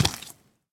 Minecraft.Client / Windows64Media / Sound / Minecraft / mob / zombie / step4.ogg
should be correct audio levels.
step4.ogg